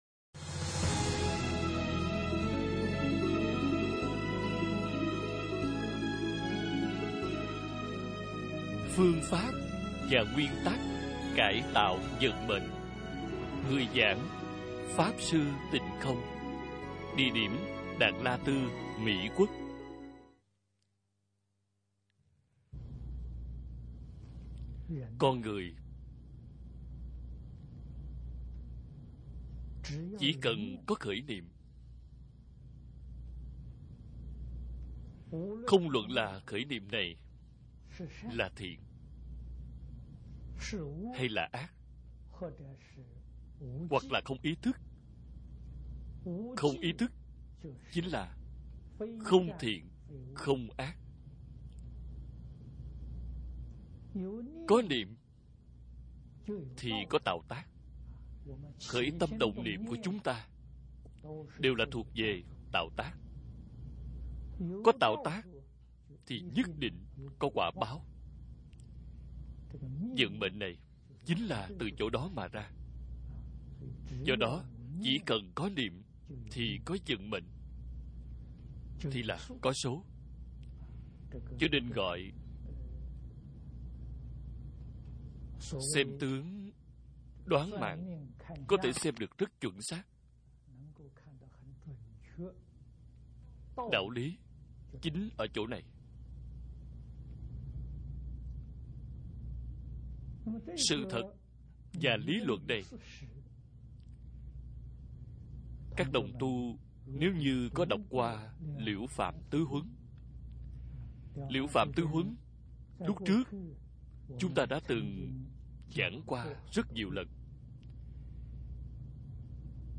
Hòa Thượng Tịnh Không giảng (Bộ 2 Tập) - Bài giảng Video